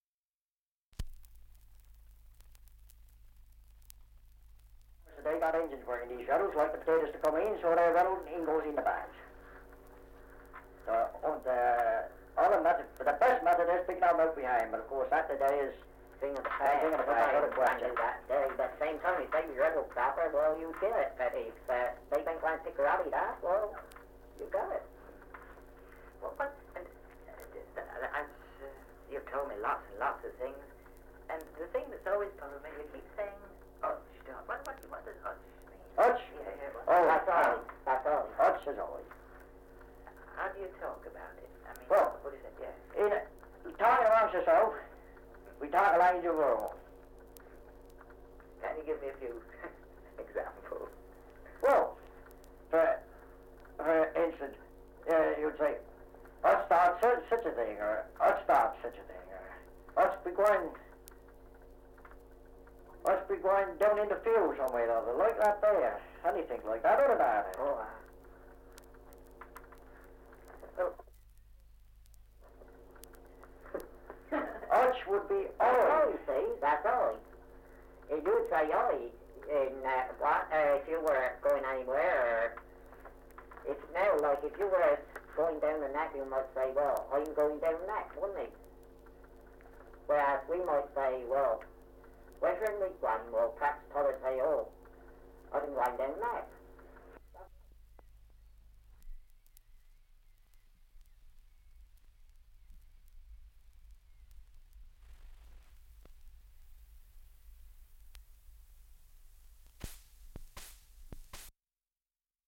Title: Dialect recording in Montacute, Somerset
78 r.p.m., cellulose nitrate on aluminium